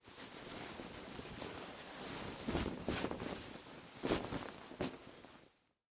Надеваем футболку через горловину